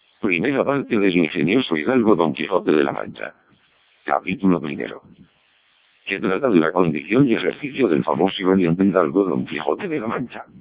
Parte de um arquivo de áudio CODEC2 recebido aqui: (